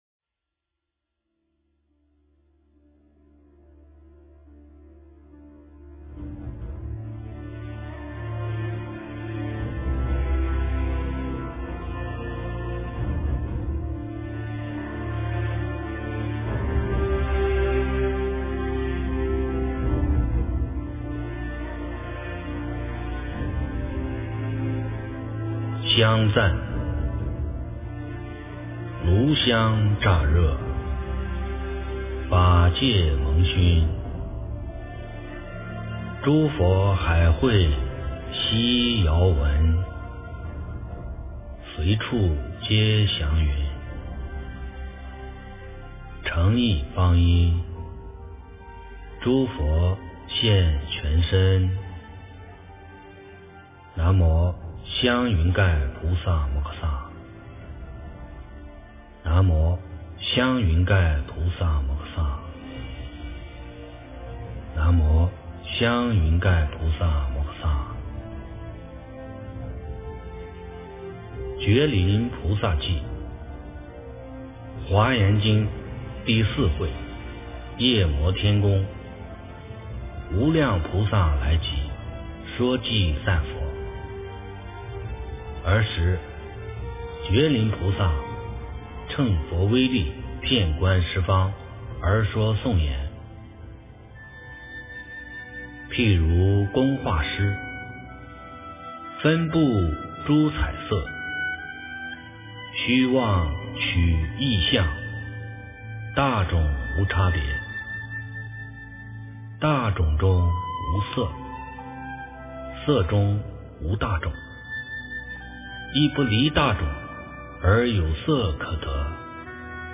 地藏菩萨本愿经卷上 - 诵经 - 云佛论坛